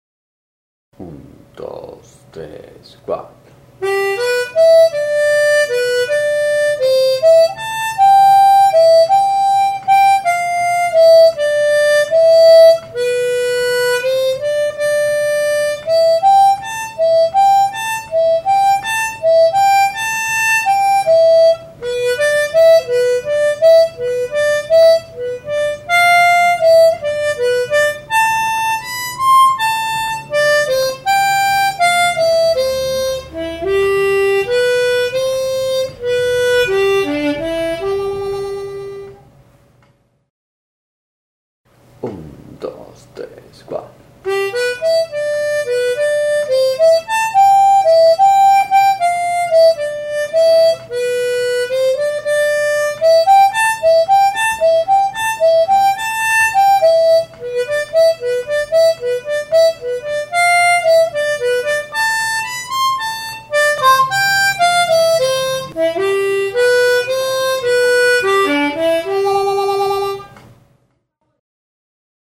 Los temas están por orden de dificultad y los archivos de audio tienen cada uno su versión lenta de práctica y la versión al tempo original más rápido.
Todos los temas están tocados con armónica diatónica de 10 celdas afinada en Do (letra C).
Tema 16.- Rock Blues básico